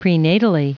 Prononciation du mot prenatally en anglais (fichier audio)
Prononciation du mot : prenatally